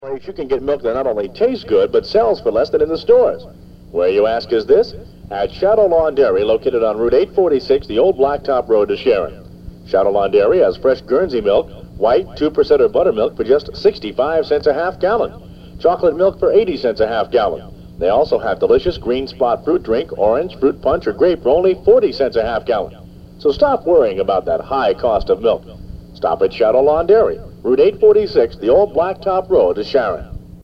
* Old Radio Commercials -